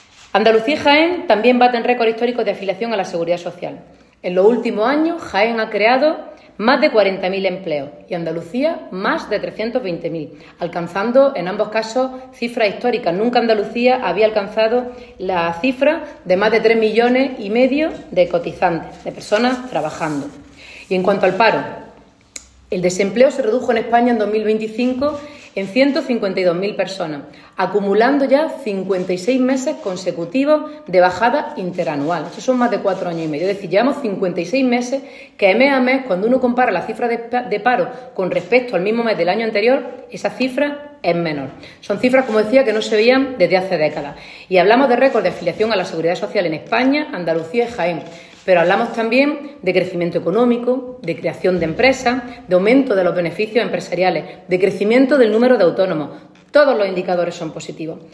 En rueda de prensa, Cobo destacó que las políticas laborales del Gobierno y el despliegue de los fondos Next Generation han permitido impulsar “un cambio de modelo productivo para adaptar la economía a la transformación tecnológica” que estamos viviendo.
Ana-Cobo-empleo-1.mp3